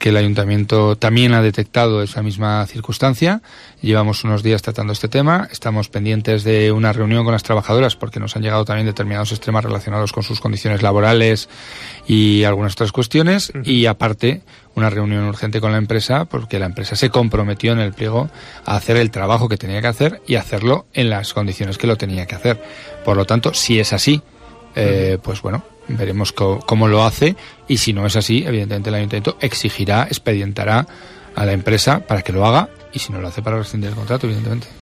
Declaraciones del alcalde de Jaca